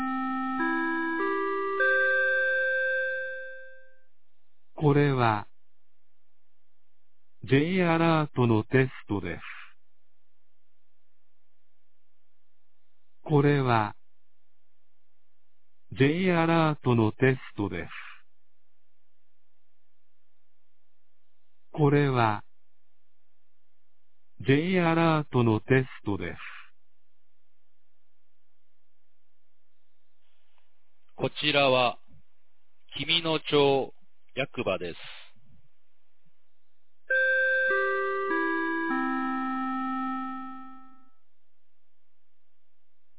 2023年11月15日 11時01分に、紀美野町より全地区へ放送がありました。